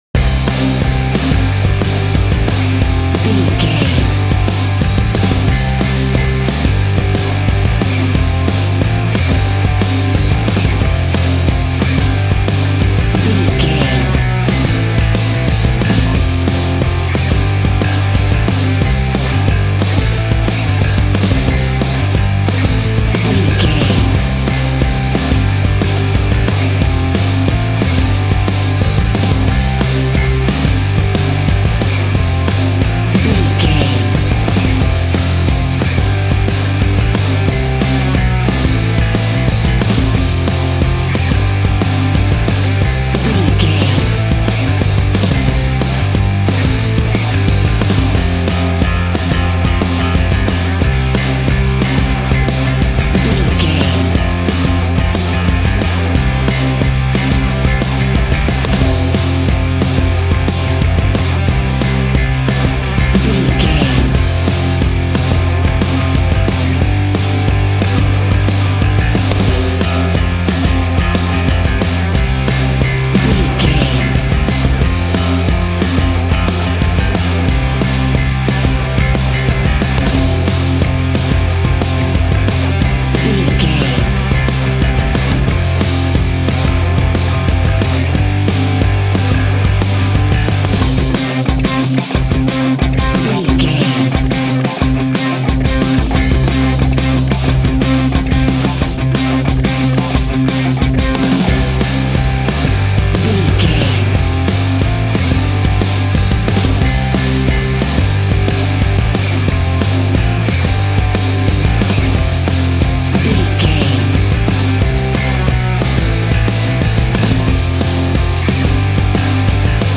Ionian/Major
groovy
powerful
electric organ
drums
electric guitar
bass guitar